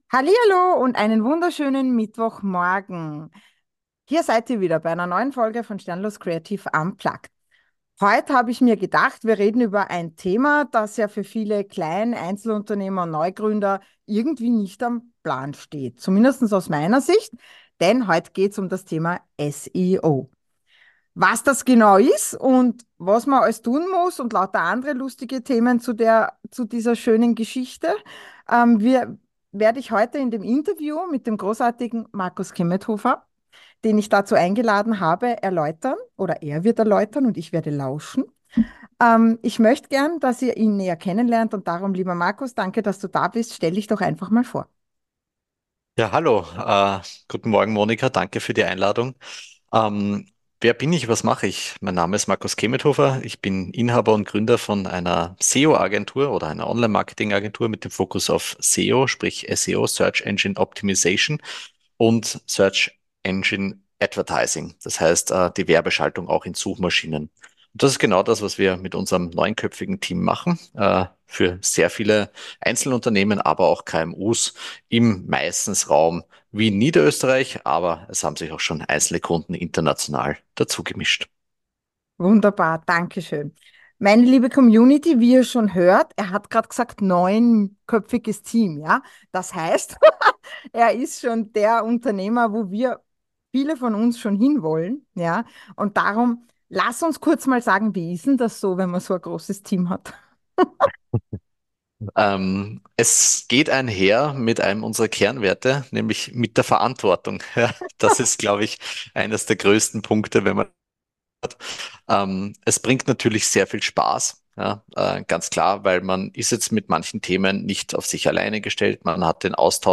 INTERVIEW SEO-search engine optimization – sagt euch doch was oder? Für uns Selbstständige liegt hier viel Potential ohne das es uns bewusst ist.